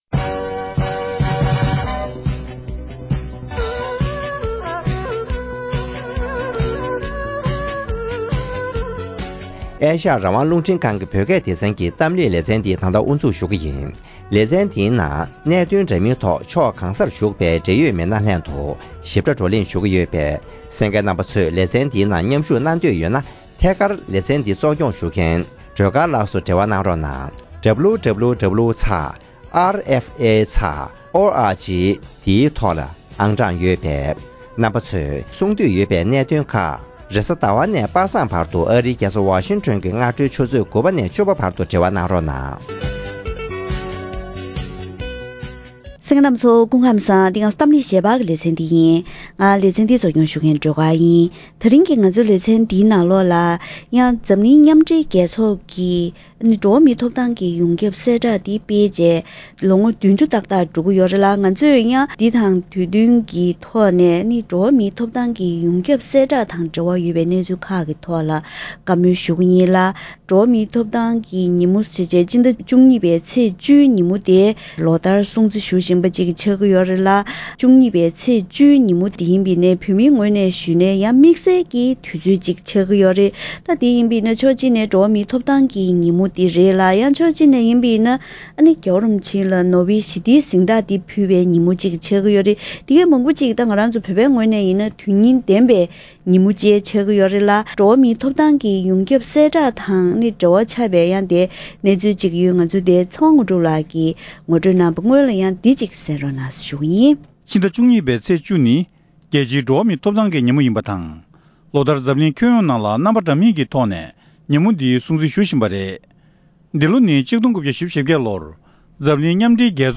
ལོ་ངོ་༣༠གོང་བོད་ཡིག་ཐོག་ཡོད་པའི་འགྲོ་བ་མིའི་ཐོབ་ཐང་གི་ཡོངས་ཁྱབ་གསལ་བསྒྲགས་འདི་འགྲེམས་སྤེལ་གནང་བའི་སྐབས་ཀྱི་གནས་ཚུལ་ཐད་གླེང་མོལ།